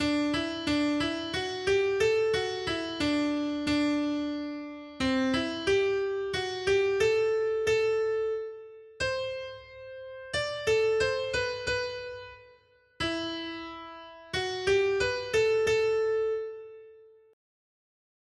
Noty Štítky, zpěvníky ol240.pdf responsoriální žalm Žaltář (Olejník) 240 Ž 19, 8 Ž 19, 10 Ž 19, 12-14 Skrýt akordy R: Hospodinovy předpisy jsou správné, působí radost srdci. 1.